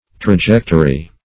Trajectory \Tra*ject"o*ry\, n.; pl.